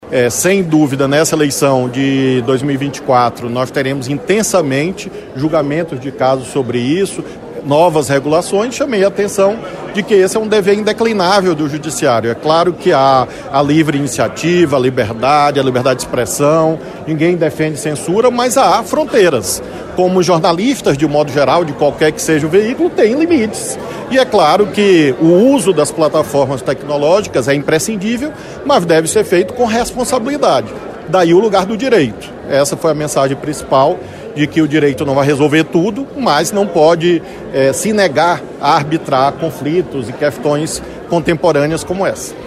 A declaração foi dada durante a participação de Dino, nesta sexta-feira (14), do Congresso Brasileiro de Direito Eleitoral, em Curitiba.
Em conversa com a imprensa, ele apontou a necessidade de um maior controle sobre as redes sociais para evitar crimes em ano eleitoral.